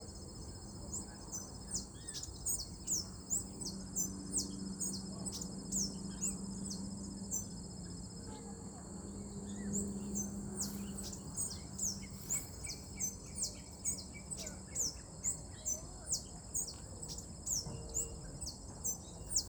Tico-tico-da-taquara (Microspingus cabanisi)
Nome em Inglês: Grey-throated Warbling Finch
Província / Departamento: Entre Ríos
Condição: Selvagem
Certeza: Fotografado, Gravado Vocal
Monterita-Litoralena_1.mp3